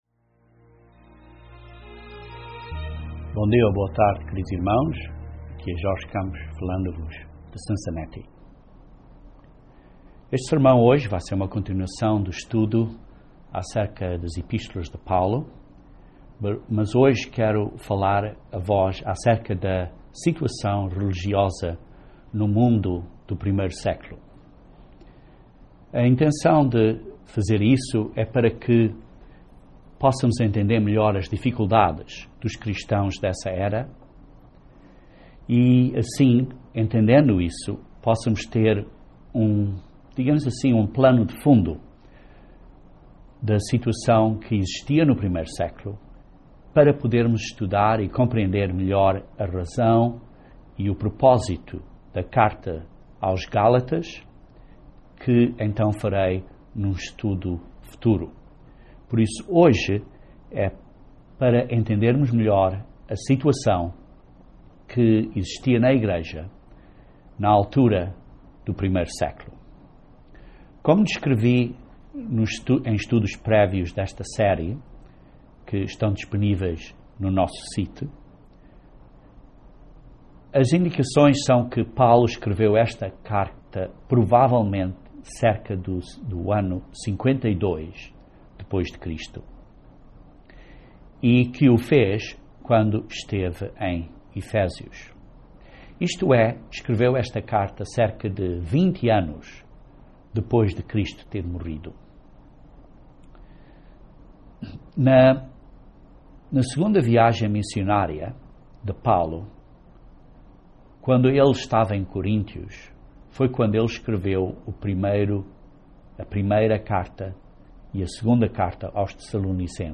Este estudo bíblico descreve o tema principal do livro de Gálatas e as influências que afetavam os Cristãos no primeiro século.